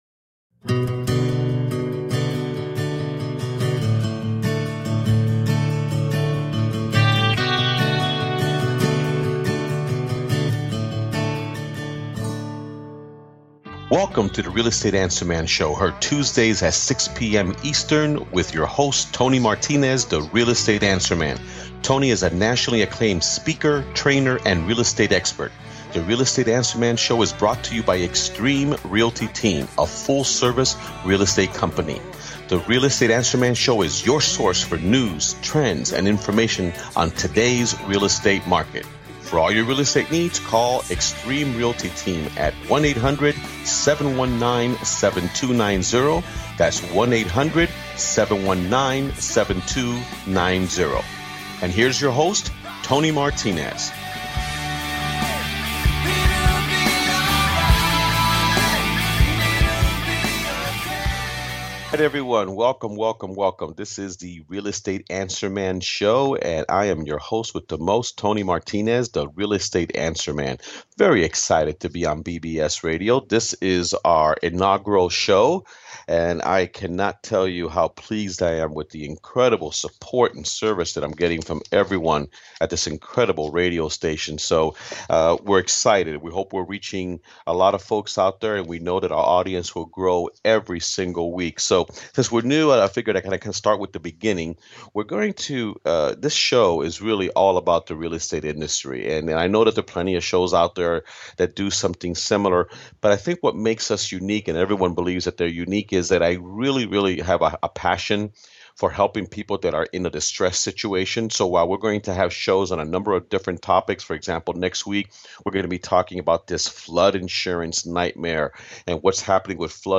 Talk Show Episode, Audio Podcast, Real Estate Answer Man Show